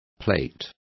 Complete with pronunciation of the translation of plaits.